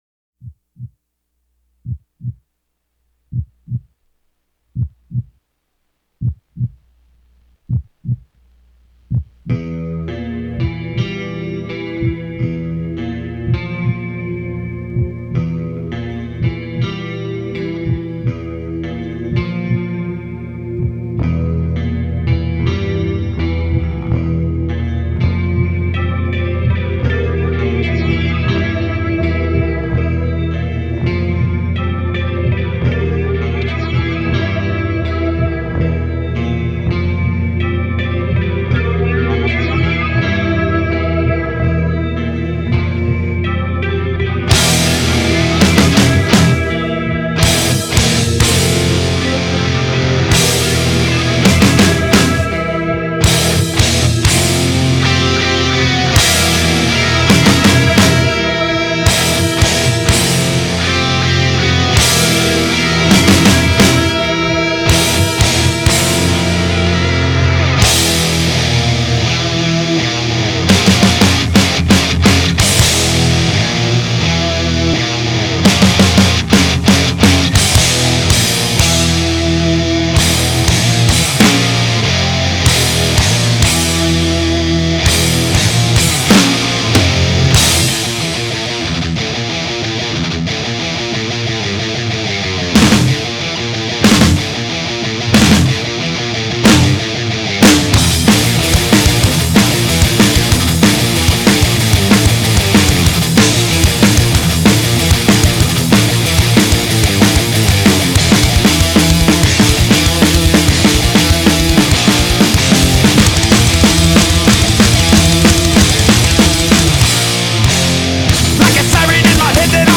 Thrash Metal, Heavy Metal